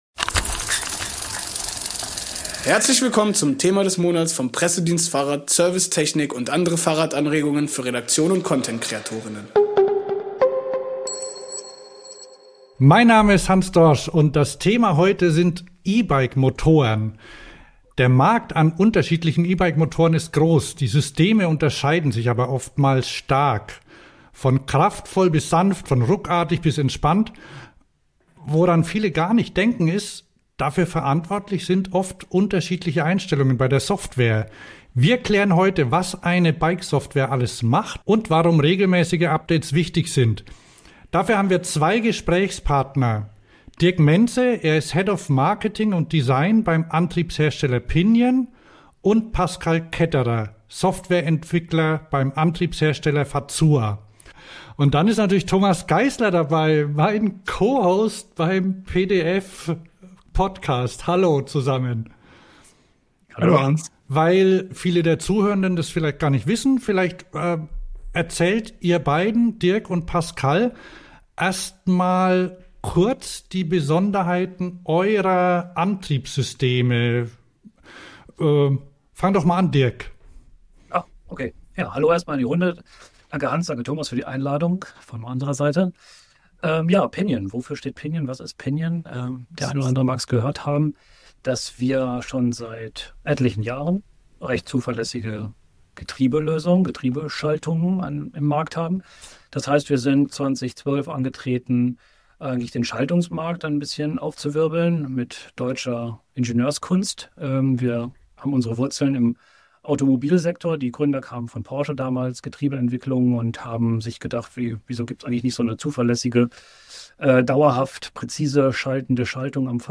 E-Bike-Motoren unterscheiden sich nicht nur in Leistung und Design, sondern vor allem in ihrer Software. Wir sprechen mit Experten von Pinion und Fazua darüber, wie Software das Fahrgefühl bestimmt, Updates den Unterschied machen - und warum weniger manchmal mehr ist.